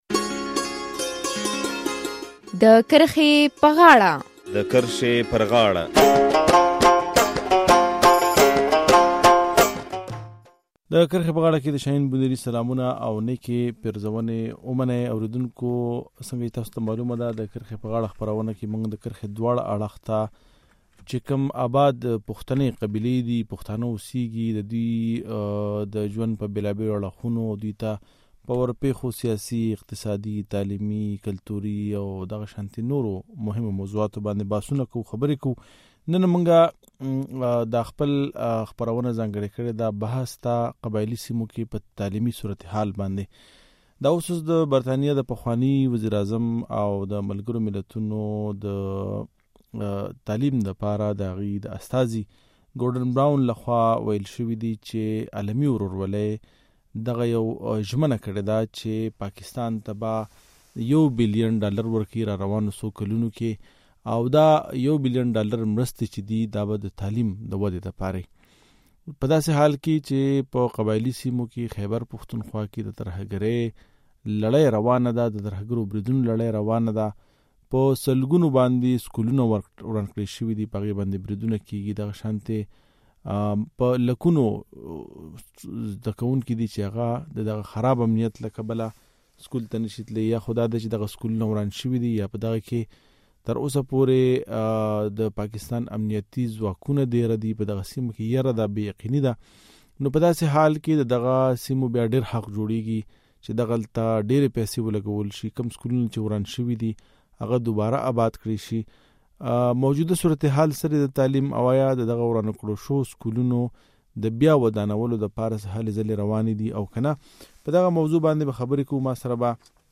په داسې حال کې چې په قبایلي سیمو کې اوس هم په سلګونو سکولونه وران پراته دي په دغه نړیوالو مرستو کې د قبایلي ولس څومره حق جوړیږي او موجوده وخت کې دغلته تعلیمي هلې ځلې څنګه روانې دي؟ د کرښې په غاړه خپرونه کې ورباندې بحث کوو.